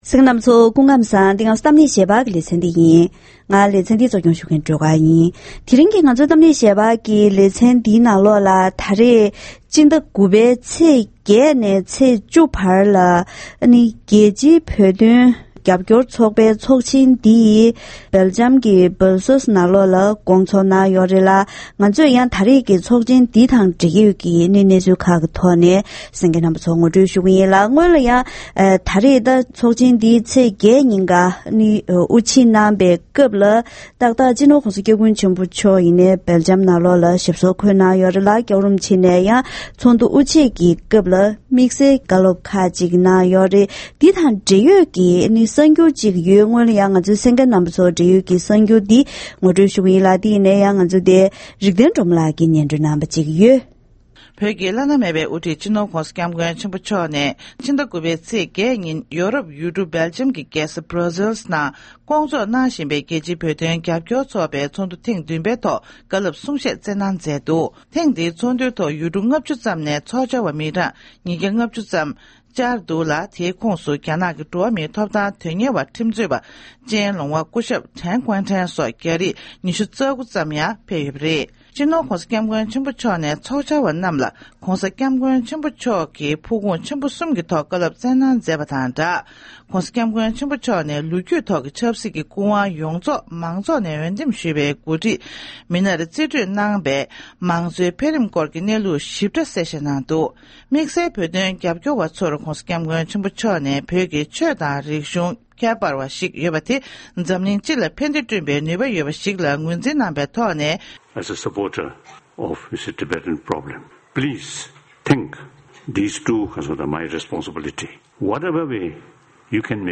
༄༅། །ཐེངས་འདིའི་གཏམ་གླེང་ཞལ་པར་ལེ་ཚན་ནང་སྦར་སེལ་ནང་རྒྱལ་སྤྱིའི་བོད་དོན་རྒྱབ་སྐྱོར་ཚོགས་པའི་ཚོགས་ཆེན་ཐེངས་བདུན་པ་འདི་སྐོང་ཚོགས་གནང་ཡོད་ལྟར་ཚོགས་ཞུགས་མི་སྣ་དང་ལྷན་ཚོགས་འདུའི་ཐོག་བོད་ཀྱི་ཁོར་ཡུག་དང་ཆབ་སྲིད་གནས་སྟངས། བོད་ནང་གི་འགྲོ་བ་མིའི་ཐོབ་ཐང་སྐོར་ལ་སོགས་པའི་འབྲེལ་ཡོད་གནད་དོན་ཐོག་བགྲོ་གླེང་ཇི་བྱུང་བཀའ་མོལ་ཞུས་པ་ཞིག་གསན་རོགས་གནང་།